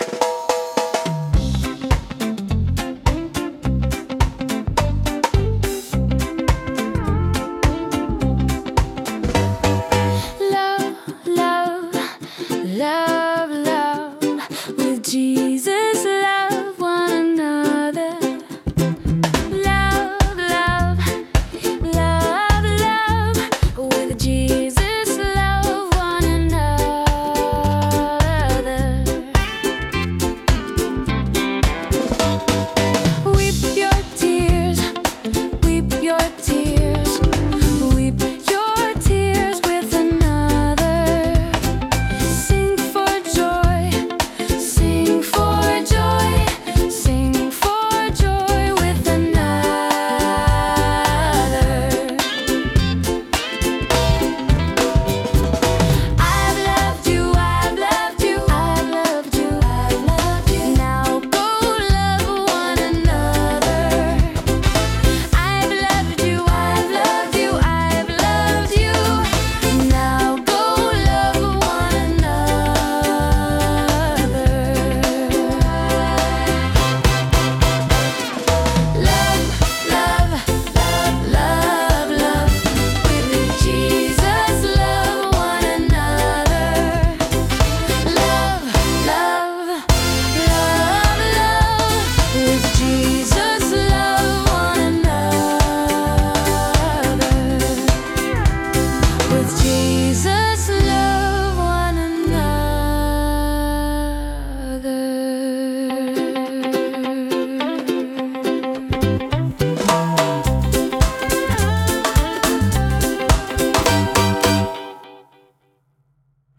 Love-one-another-BASIC-VOCAL-Bflat.2-2.wav